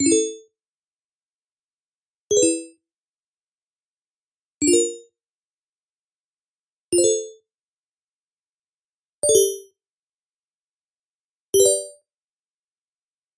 Звуки уведомления чата
Несколько звуков для уведомлений в чате